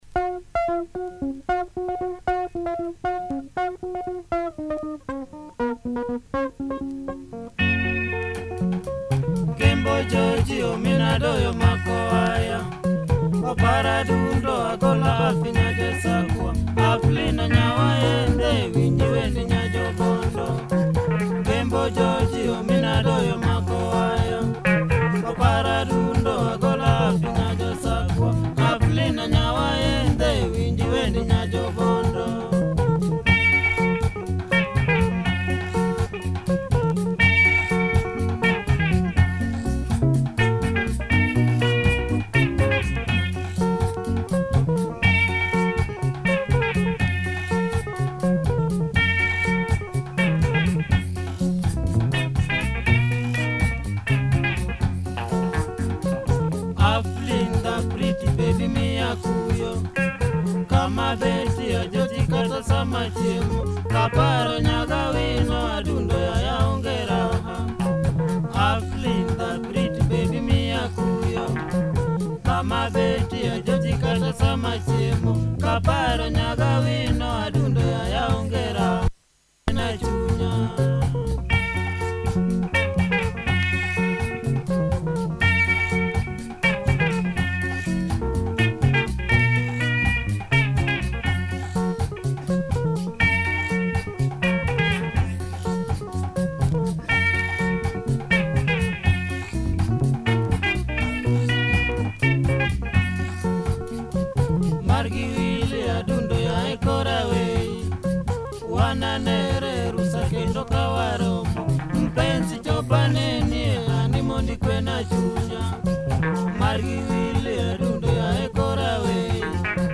Good early LUO benga from 1974, spacey breakdown!